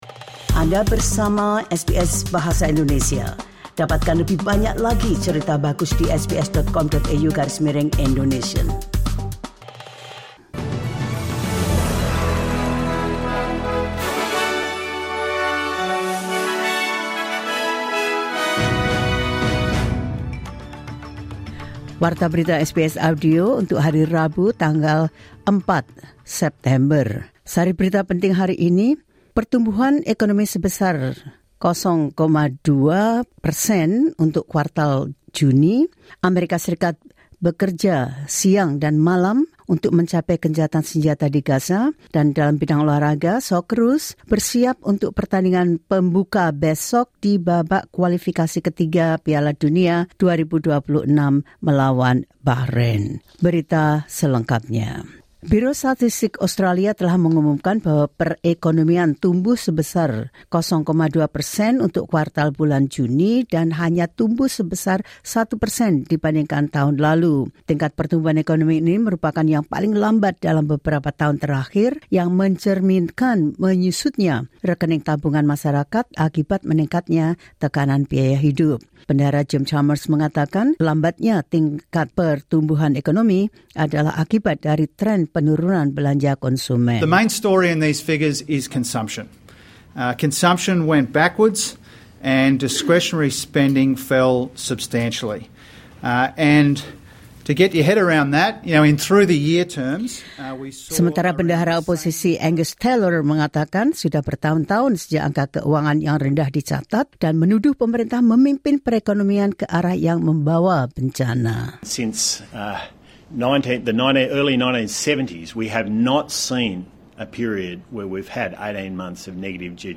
The latest news of SBS Audio Indonesian program – 04 Sep 2024.